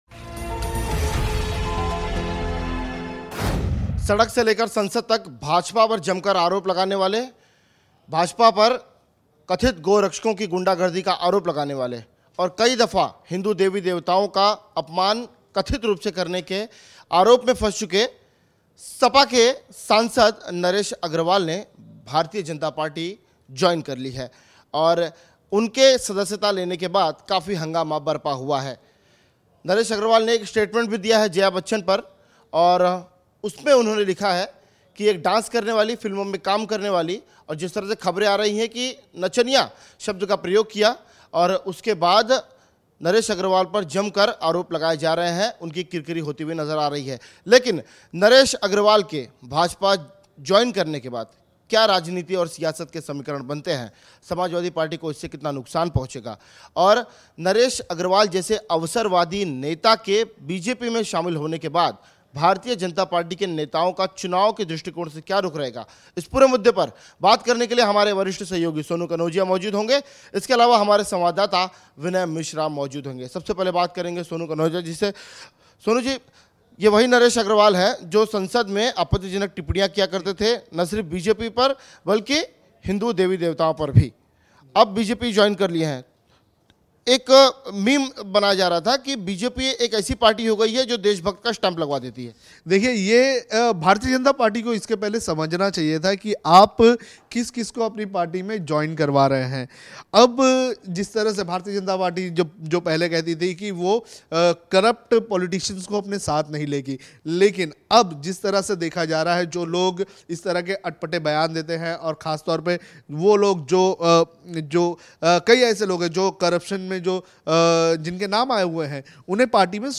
News Report / व्हिस्की में विष्णु और रम में राम देखनेवाले Naresh Agarwal की शुद्धि कर पाएगी बीजेपी ?